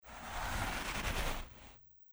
在很深的积雪里行走的脚步声－YS070525.mp3
通用动作/01人物/01移动状态/02雪地/在很深的积雪里行走的脚步声－YS070525.mp3
• 声道 立體聲 (2ch)